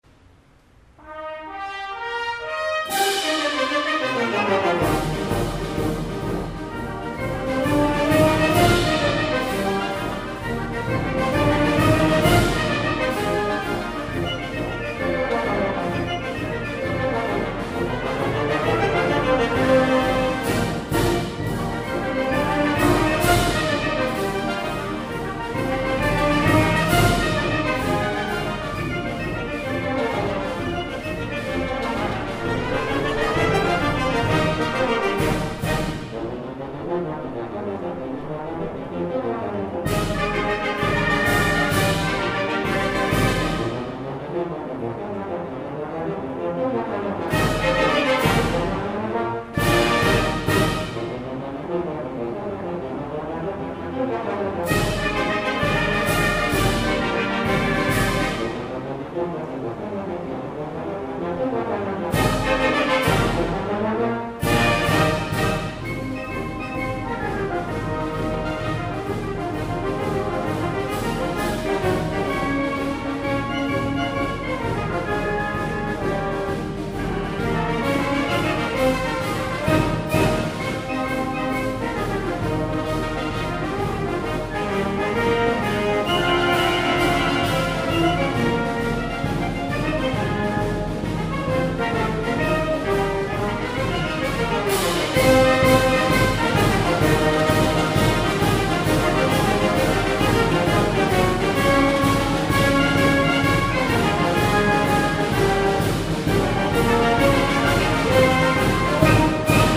Música costarricense interpretada por la Banda Nacional de Cartago